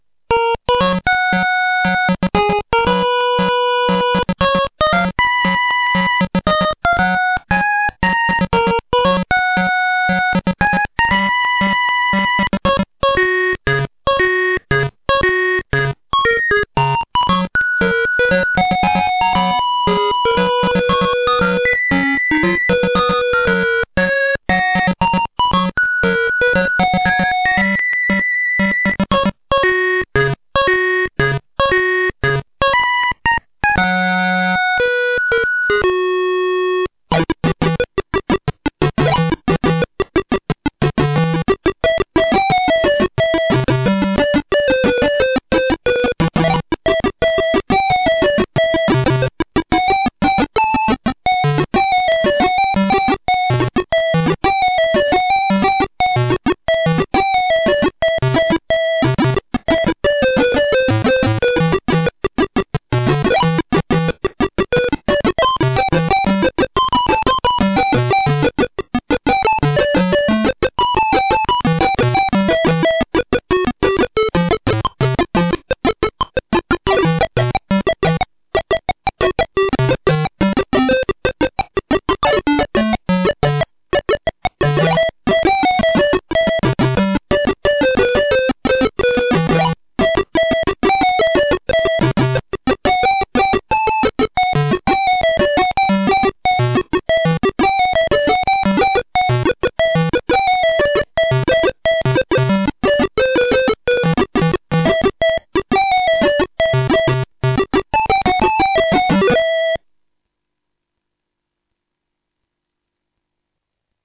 YM_demo.mp3